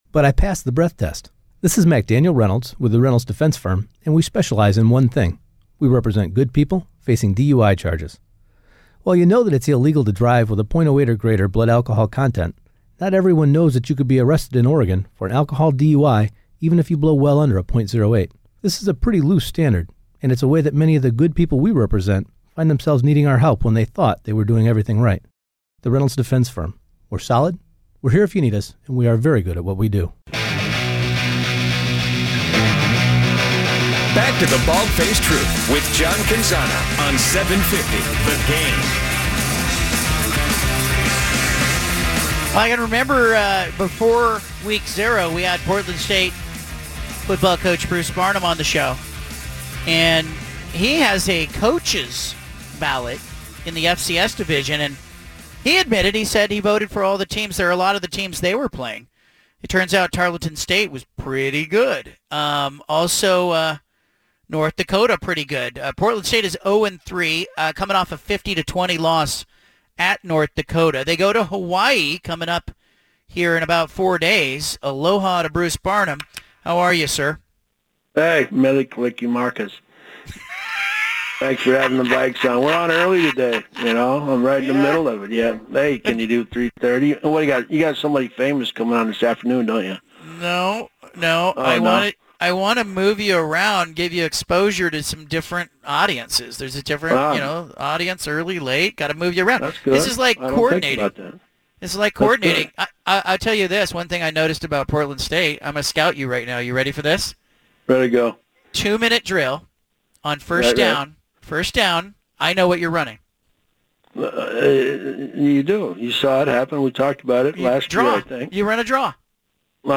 BFT Interview